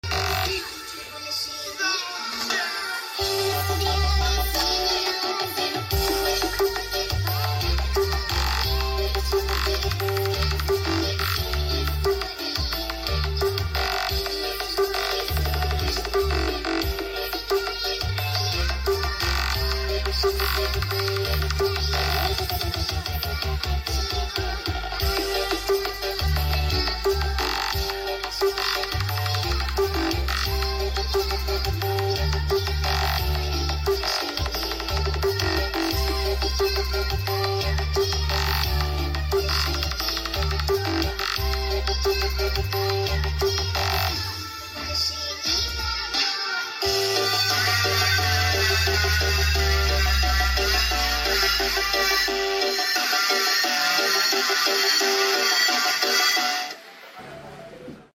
CHECK SOUND UJI COBA BOX sound effects free download